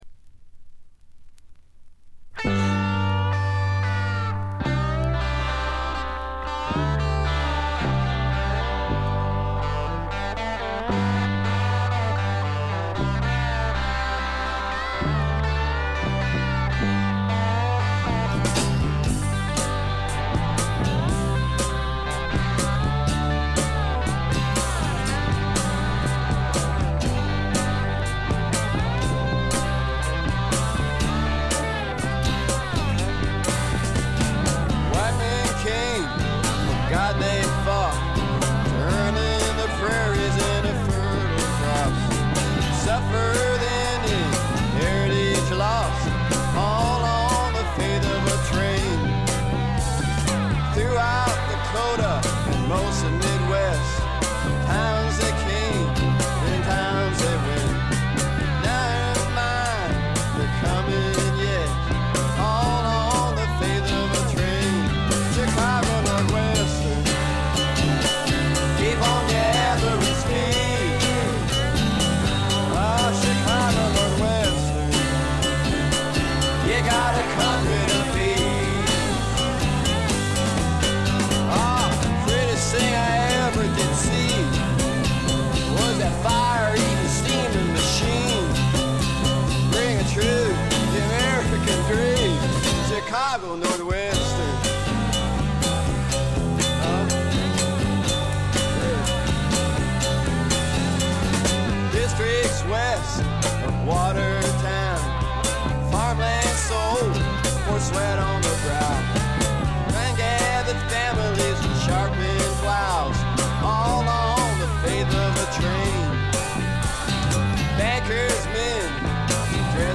へヴィー・ブルース、ハード・スワンプな名作を残しました。
試聴曲は現品からの取り込み音源です。
electric and acoustic guitars
steel guitar, backing vocals, mandolin, marimba
Recorded at I.B.C. Studios, London, September 1969